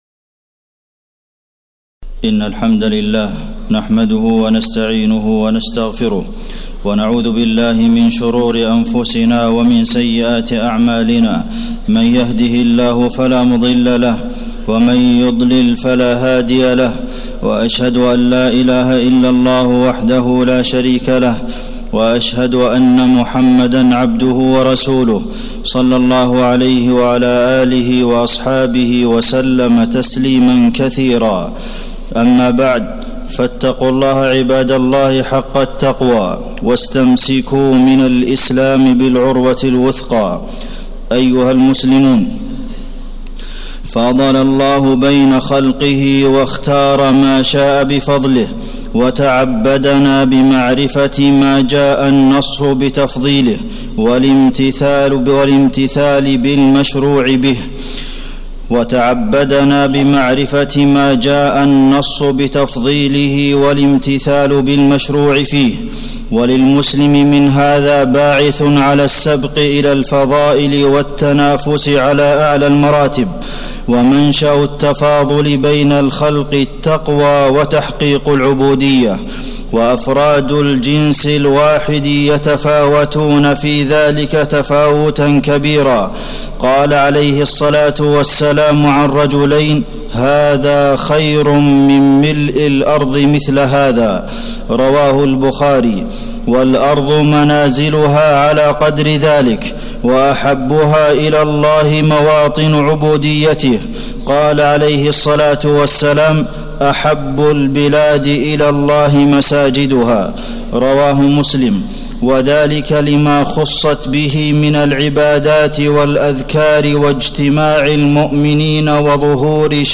تاريخ النشر ٢٤ ربيع الأول ١٤٣٨ هـ المكان: المسجد النبوي الشيخ: فضيلة الشيخ د. عبدالمحسن بن محمد القاسم فضيلة الشيخ د. عبدالمحسن بن محمد القاسم مكانة المساجد وتعظيمها